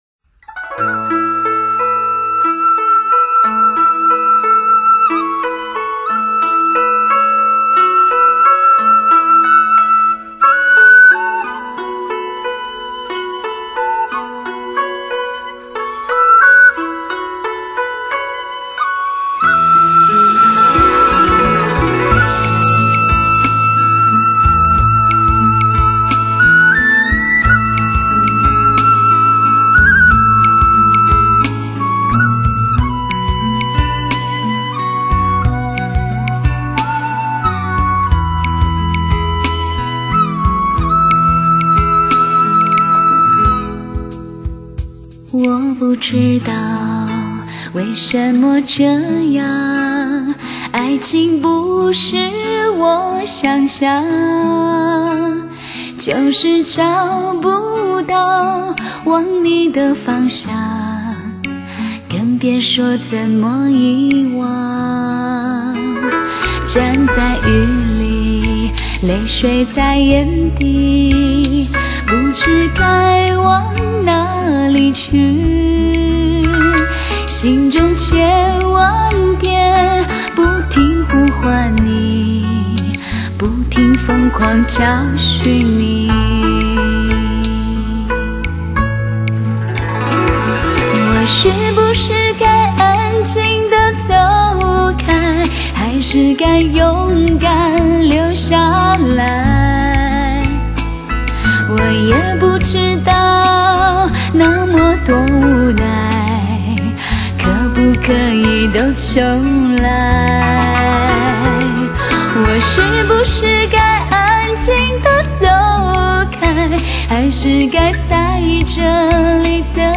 将精品音乐以超高解析度处理，充分迎合汽车音频输出、抗失真滤波器技术等汽车歌乐独特系统，
使之音乐色彩更鲜明、音质更细腻，让你在“汽车+音乐”的美妙境界里得到人生的升华……